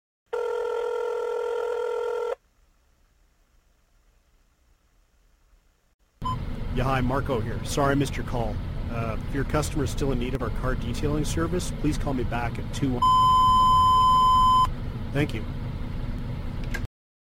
The Voice Lead Generator drops your promo message right into voicemail boxes, no cold calling needed!